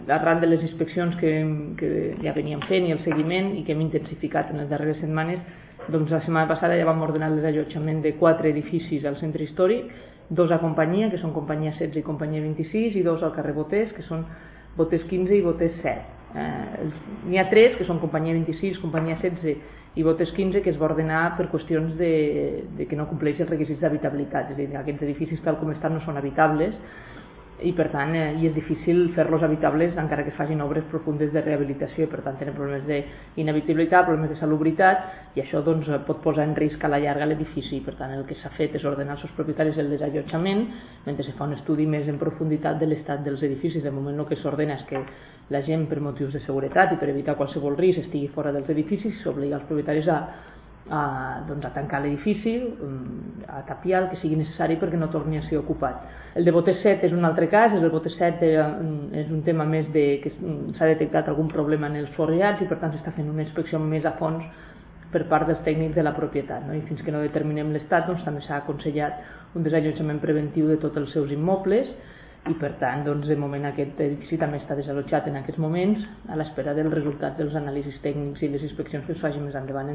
(tall de veu de la 1a tinent d'alcalde, Marta Camps)
tall-de-veu-de-la-1a-tinent-dalcalde-marta-camps